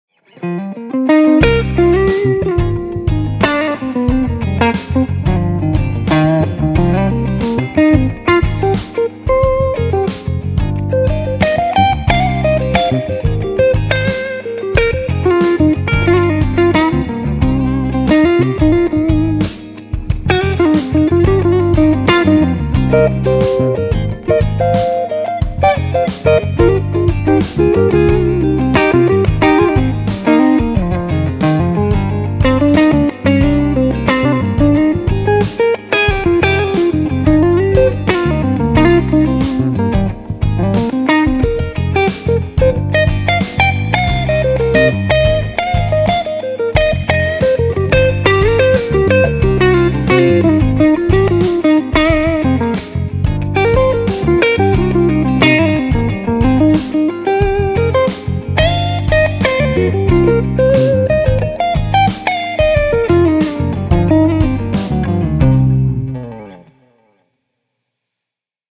The bass is tight for a matter of speaking, not flubby.
The treble frequencies are clear with just enough brightness but not too dark when they compress.
63-neck-clean.mp3